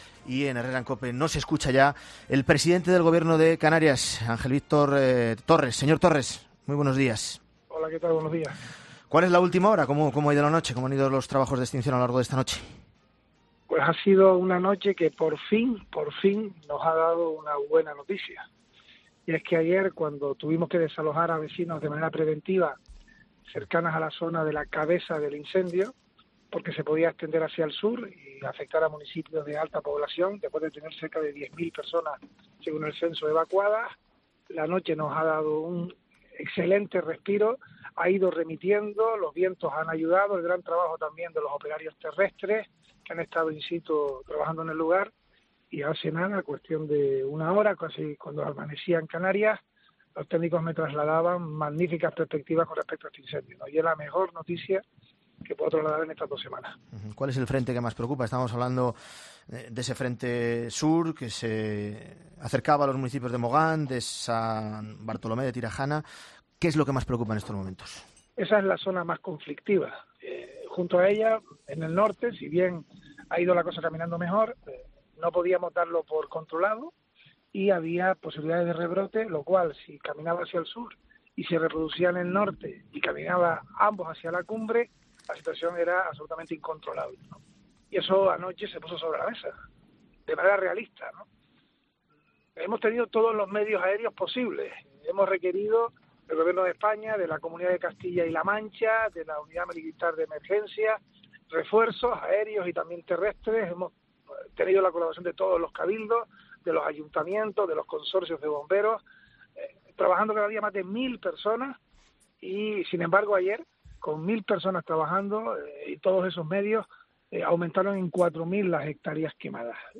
El presidente del Gobierno de Canarias, Ángel Víctor Torres, ha confirmado en 'Herrera en COPE' que el  incendio forestal de Gran Canaria ha comenzado a "remitir" esta madrugada después de tres jornadas completas ardiendo sin posibilidad de control y ha perdido potencial.